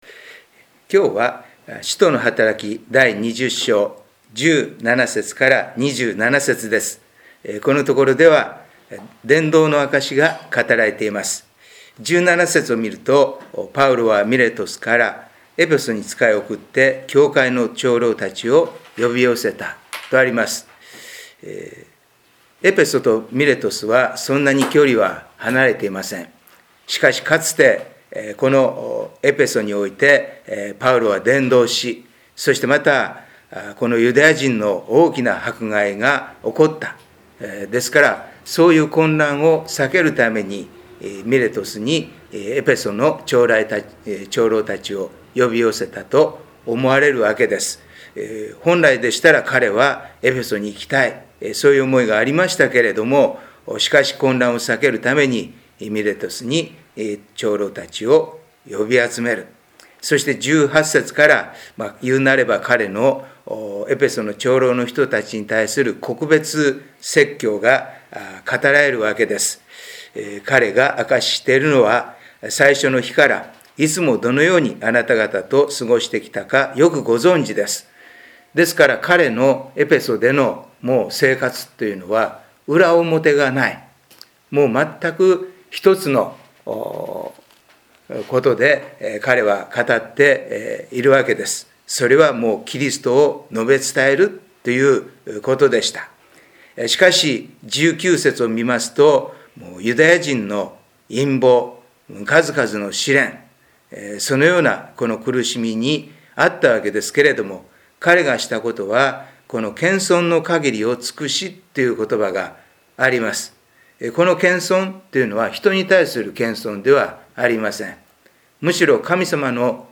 礼拝メッセージ2020│日本イエス・キリスト教団 柏 原 教 会